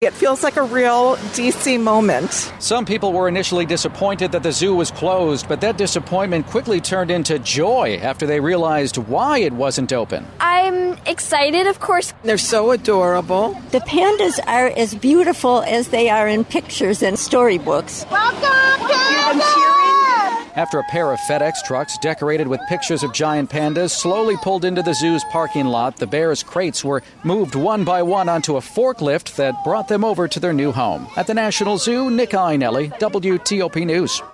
3-zoo-pandas-nia.wav